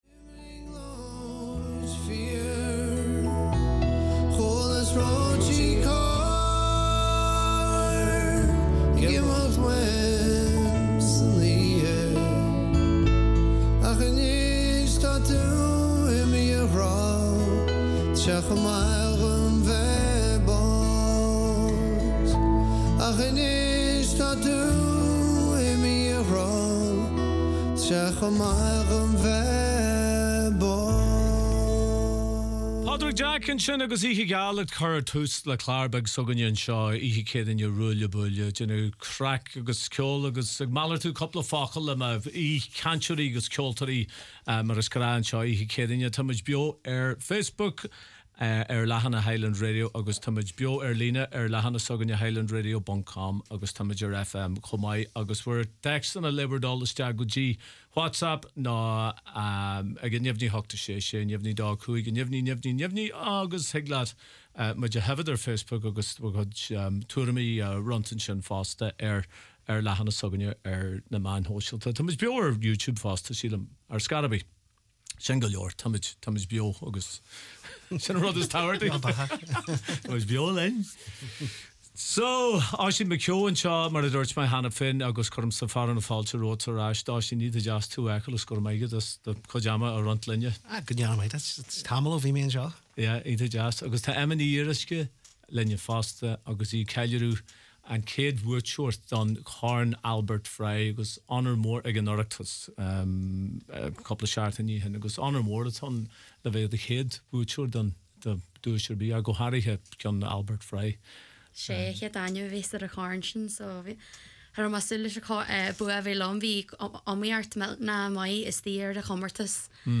BEO le ceol